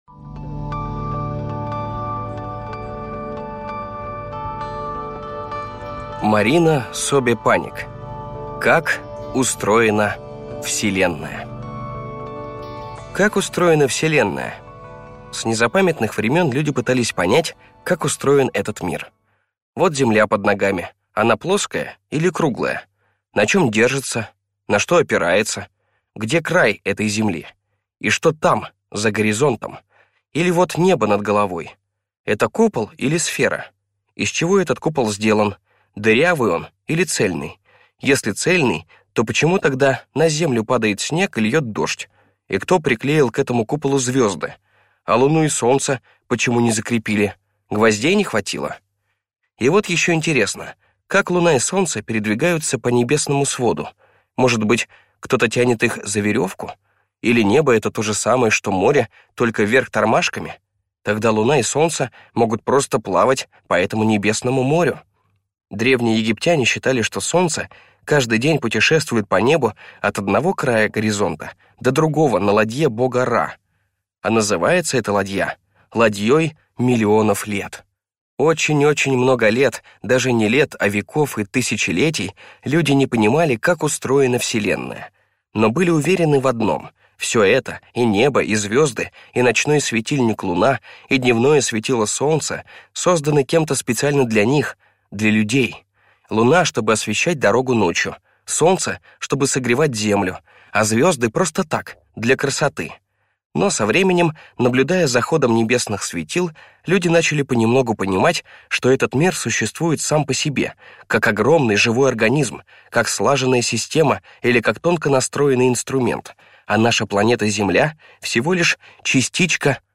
Аудиокнига Как устроена Вселенная?